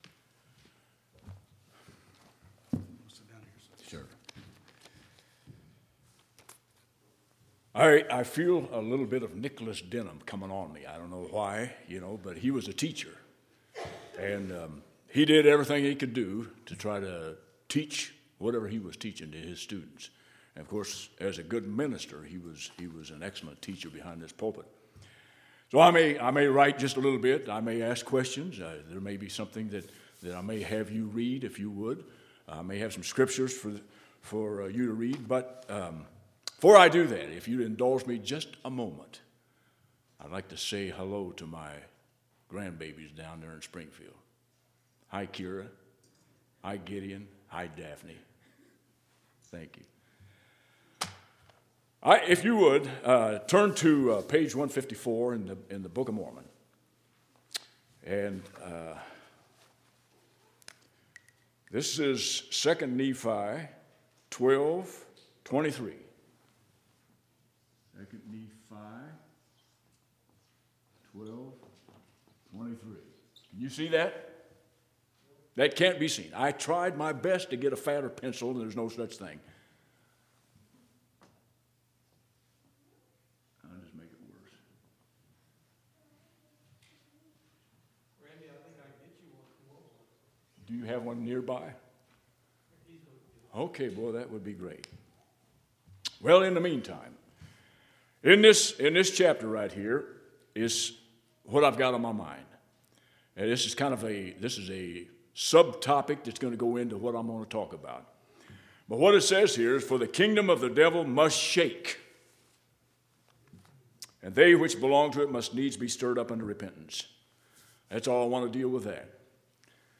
1/8/2012 Location: Temple Lot Local Event